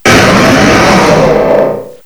cry_not_mega_aggron.aif